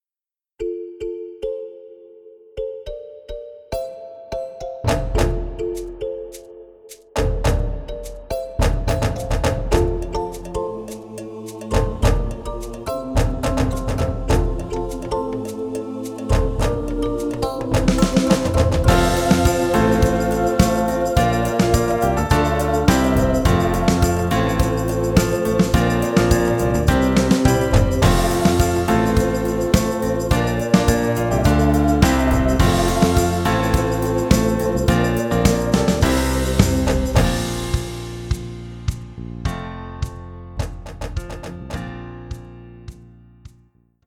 Musically, the show has lots of African rhythms and instrumentation.
Song Of Our Spirit (Backing Only Snippet) Tuesday, 11 April 2017 04:20:23